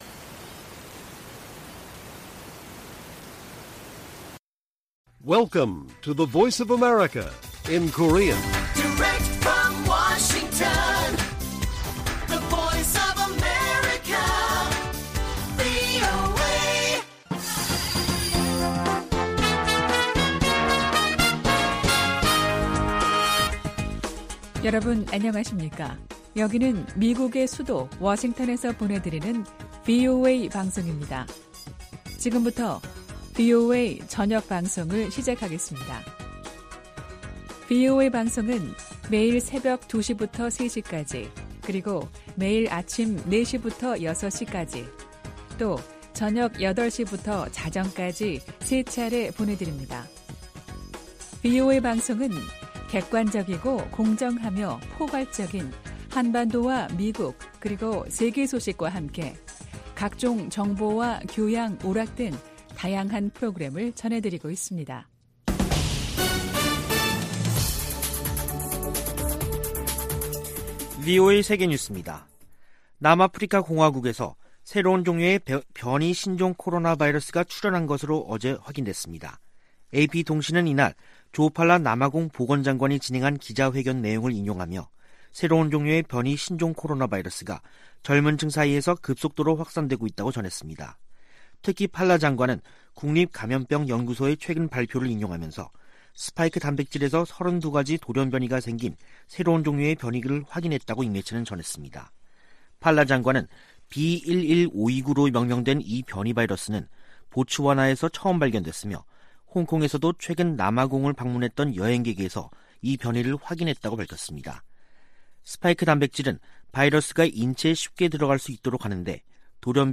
VOA 한국어 간판 뉴스 프로그램 '뉴스 투데이', 2021년 11월 26일 1부 방송입니다. 미 국무부는 북한의 계속된 핵 활동을 규탄하면서, 북한과의 비핵화 대화를 추구하고 있다고 밝혔습니다. 미국 상무부가 북한 유령회사에 미국과 다른 국가의 기술을 판매한 중국 기업 등, 국가안보에 위협이 되는 해외 기업들을 수출 규제 대상으로 지정했습니다. 북한 국영 고려항공이 또다시 유럽연합 회원국 내 운항이 엄격히 제한되는 항공사로 지정됐습니다.